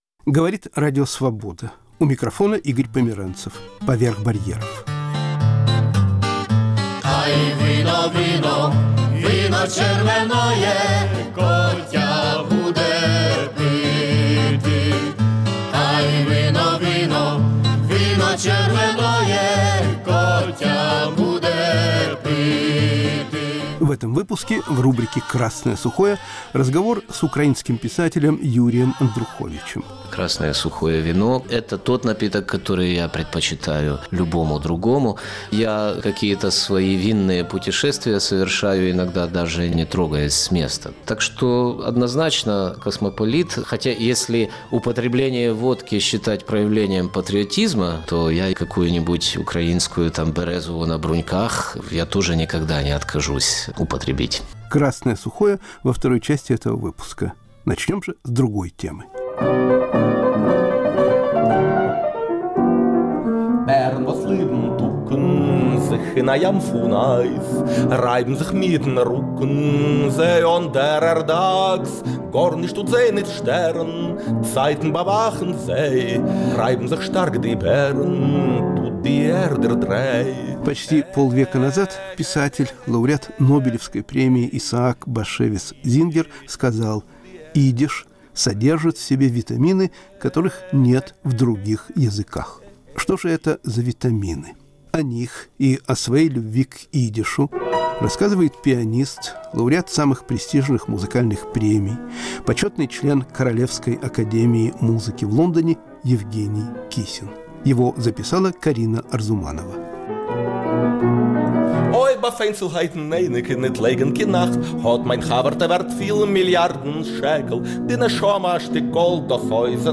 Разговор с филологами, редкие записи фольклористов.*** «Красное сухое» с украинским писателем Юрием Андруховичем.